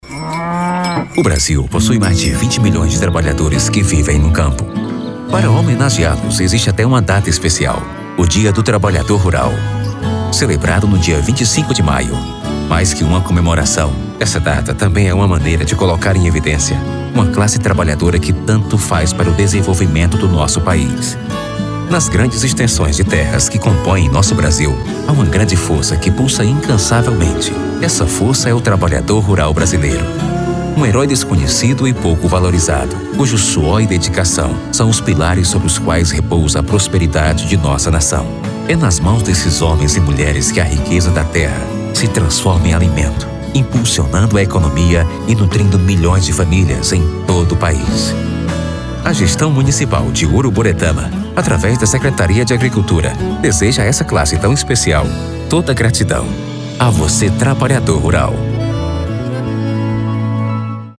Institucional: